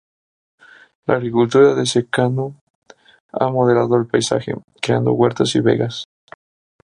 /modeˈlado/